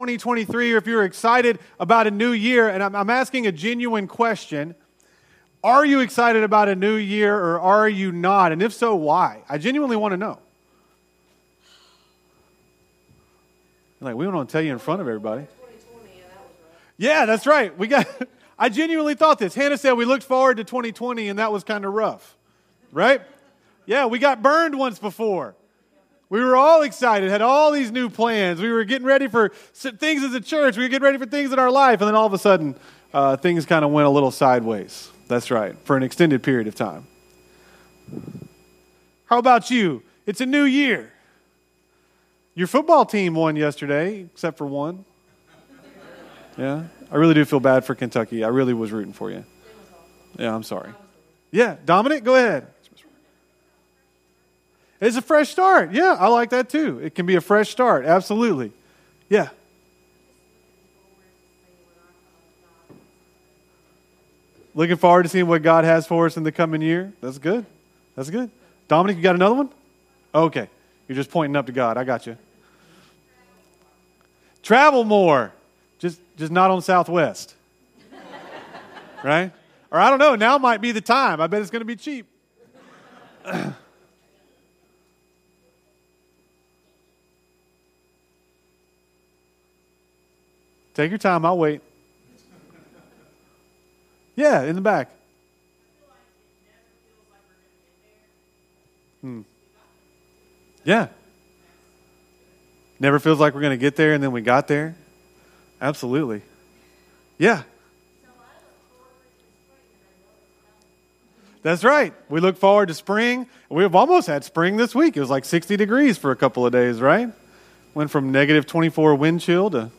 Sermons | Hilldale United Methodist Church